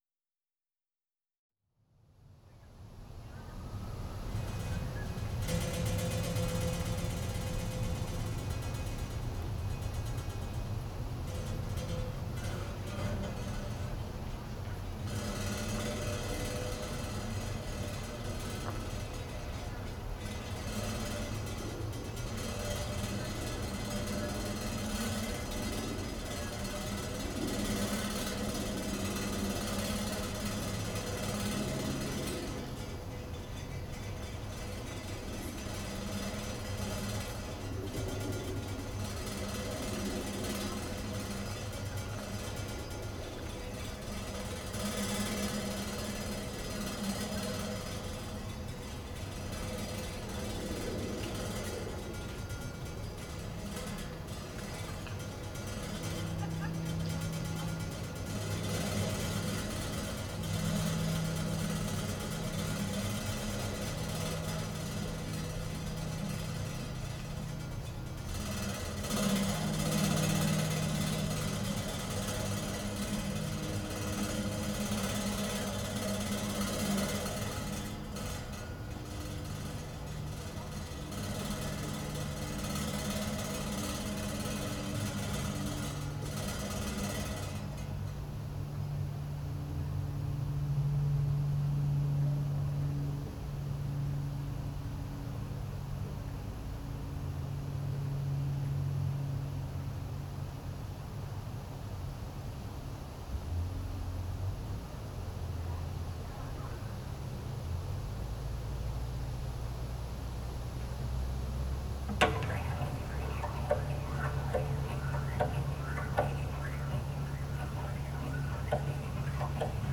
for piano and field recording
Composition for forest &inside piano